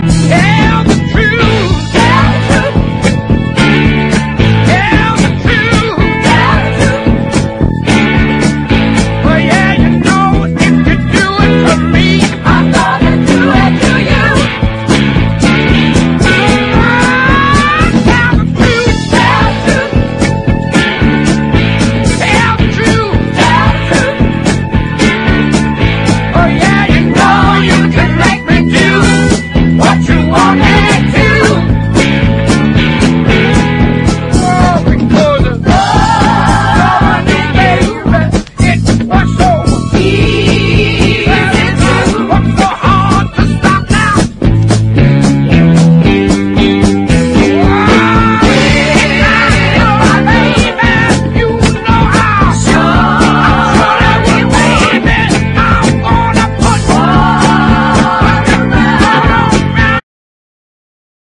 ROCK / 60'S / BUBBLEGUM ROCK / PSYCHEDELIC ROCK
ウキウキなメロディーがグイグイ溢れ出すバブルガム・ロック名曲！